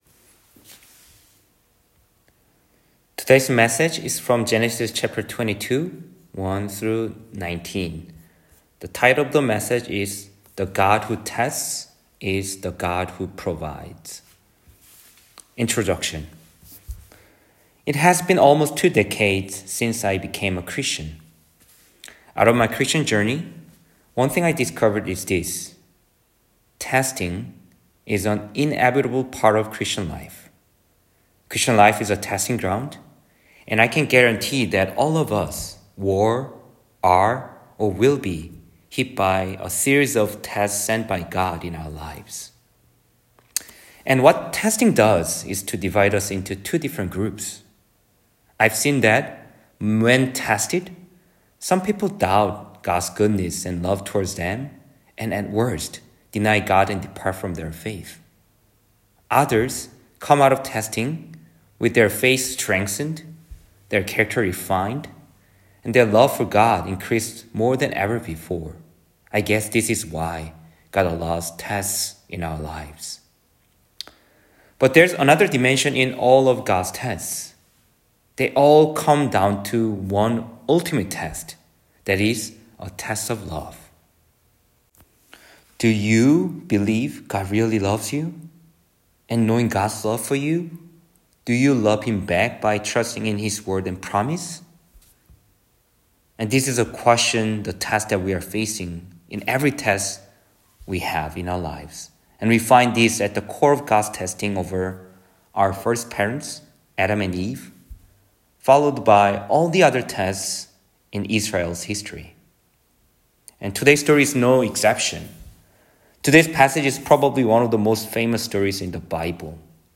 [주일 설교] 창세기 22:1-19